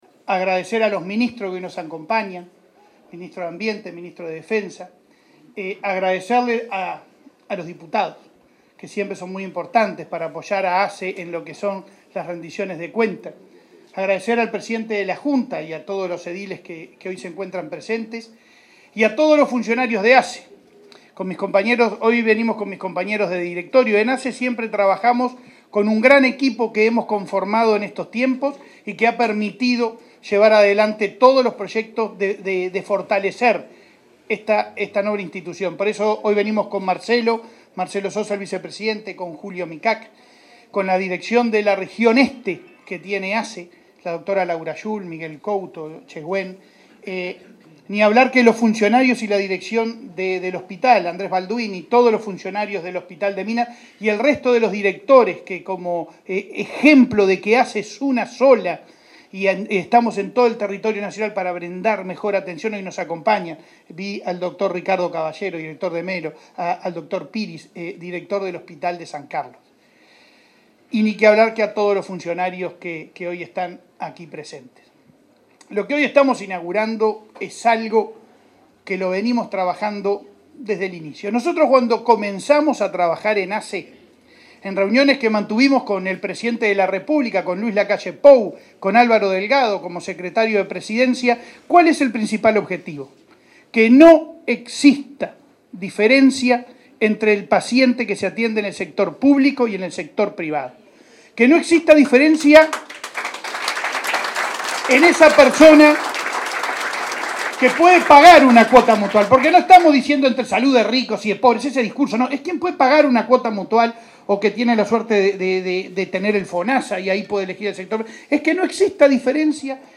Palabras de presidente de ASSE, Leonardo Cipriani
El presidente de ASSE, Leonardo Cirpiani, disertó en la ceremonia correspondiente.